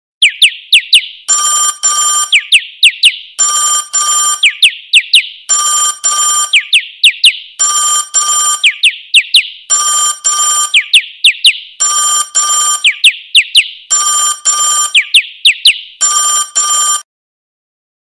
Catégorie: Animaux